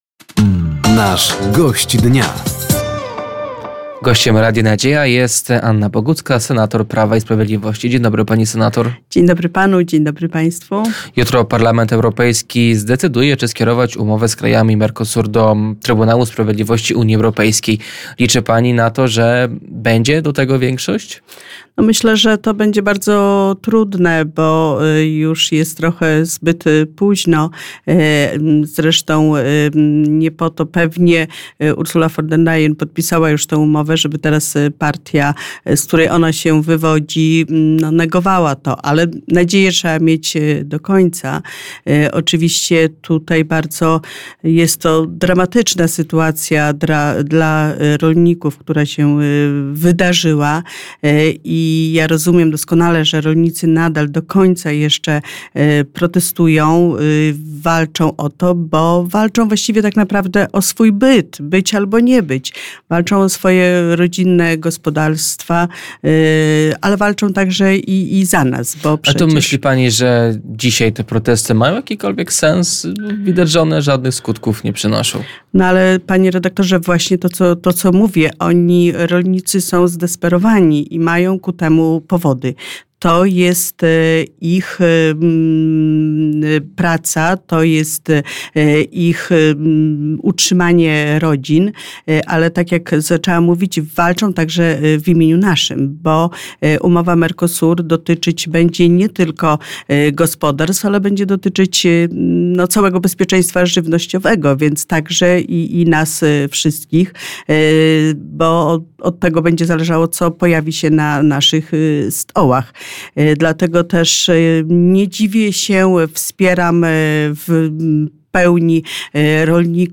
Gościem Dnia Radia Nadzieja była senator Prawa i Sprawiedliwości Anna Bogucka. Tematem rozmowy była między innymi umowa UE-Mercosur i reforma edukacji.